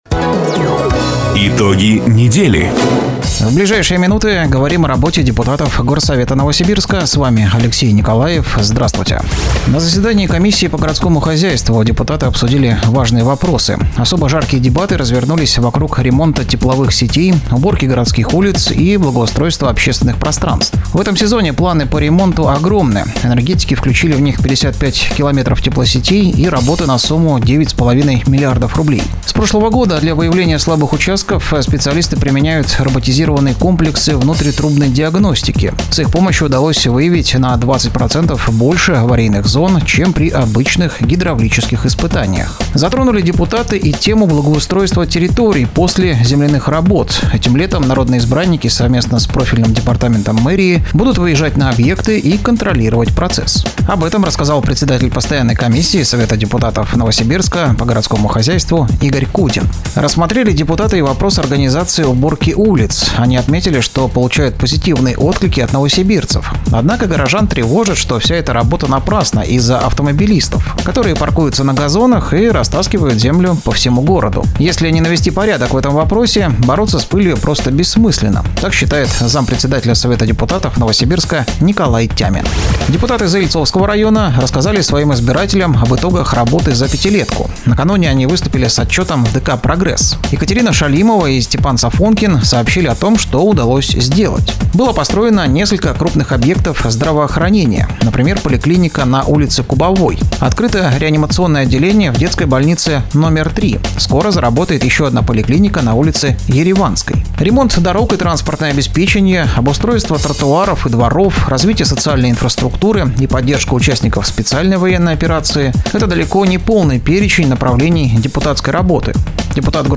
Запись программы "Итоги недели", транслированной радио "Дача" 03 мая 2025 года